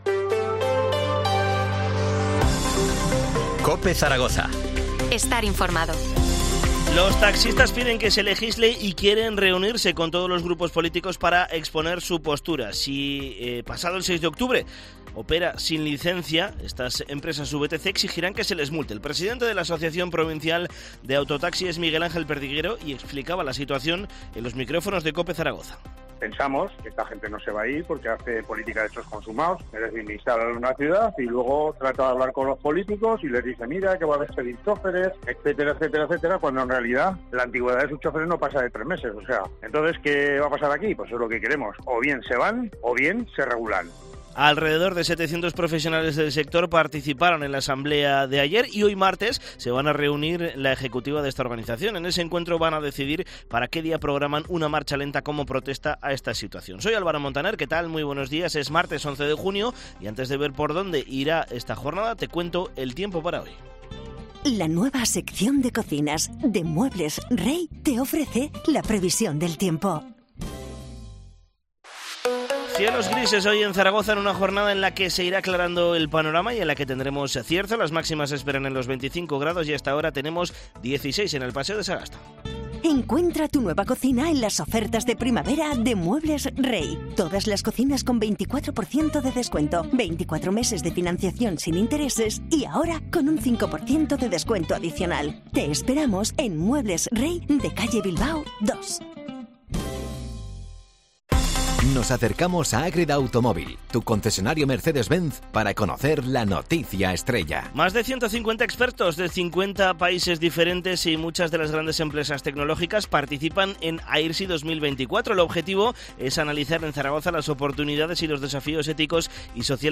AUDIO: Titulares del día en COPE Zaragoza